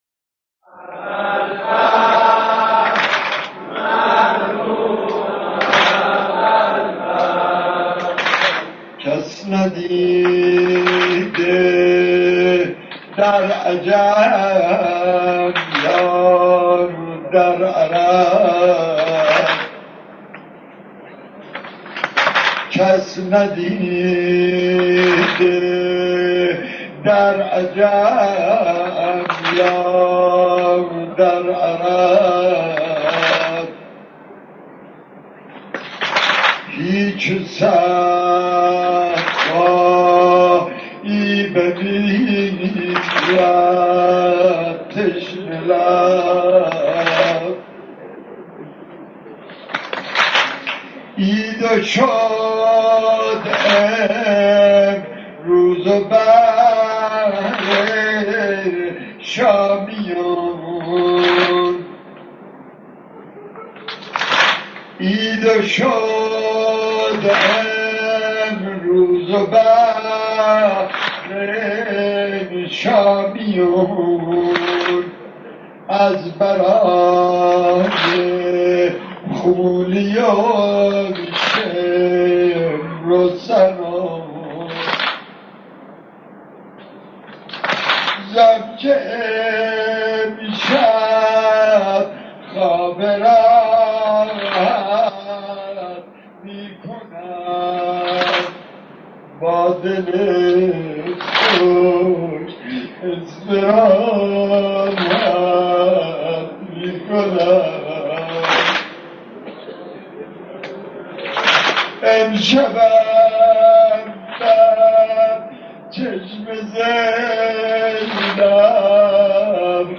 در پرده عشاق، صدای مداحان و مرثیه‌خوانان گذشته تهران قدیم را خواهید شنید که صدا و نفسشان شایسته ارتباط دادن مُحب و مَحبوب بوده است.
بخش شعر زمینه‌خوانی سینه‌زنی در روز تاسوعا